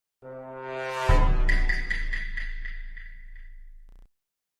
Amongus Sus Effect - Botão de Efeito Sonoro